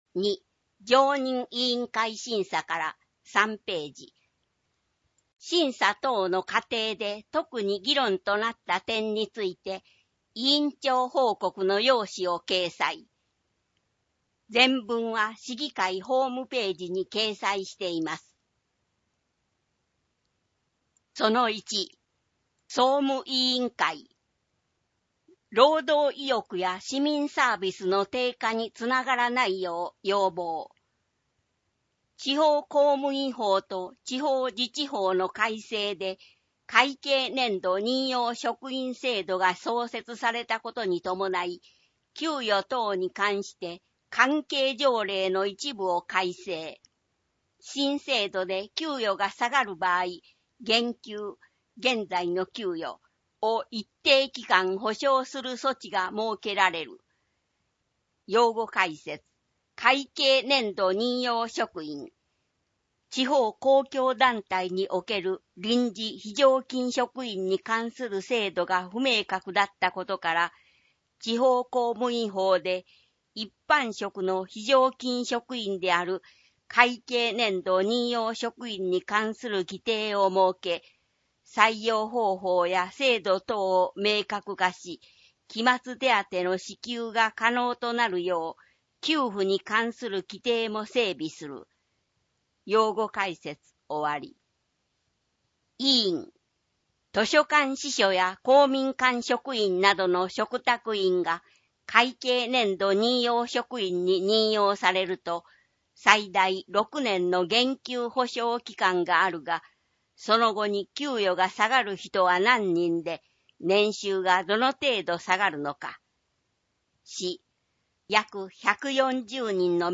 声の市議会だより（音声版「岡山市議会だより」）は、ボランティア「岡山市立図書館朗読奉仕の会」のご協力により作成しています。